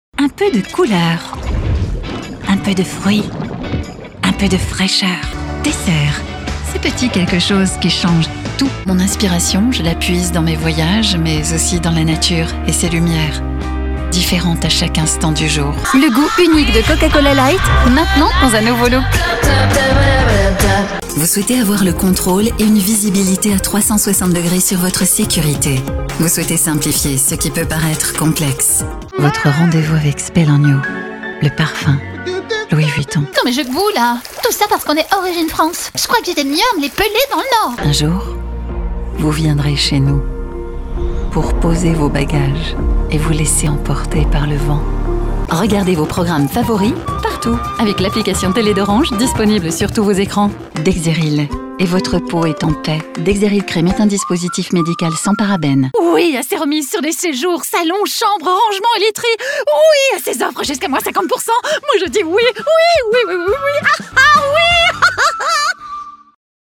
Female
Assured, Authoritative, Bright, Bubbly, Character, Cheeky, Children, Confident, Cool, Corporate, Deep, Engaging, Friendly, Gravitas, Natural, Posh, Reassuring, Sarcastic, Smooth, Soft, Streetwise, Wacky, Warm, Witty, Versatile, Young
Microphone: Neumann U87 + TLM103
Audio equipment: Apollo twin + RME fire Face ; Manley Vox Box, Booth acoustically treated